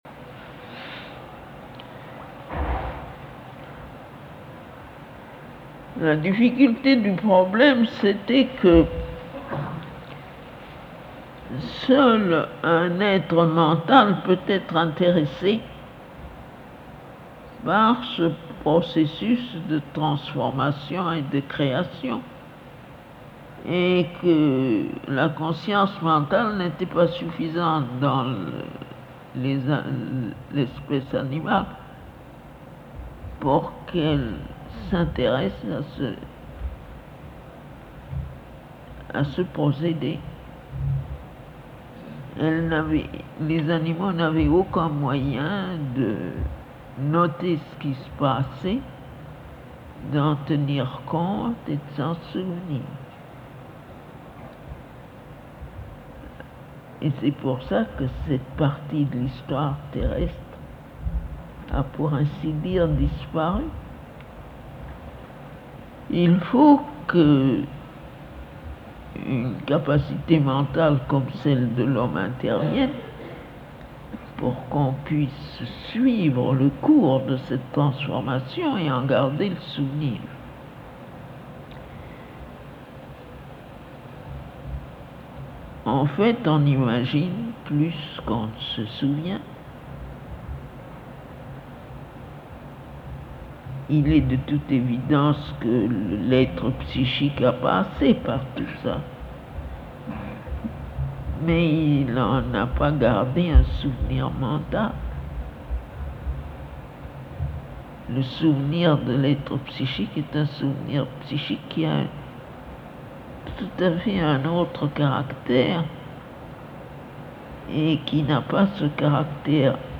Mother’s conversations with French Disciple Satprem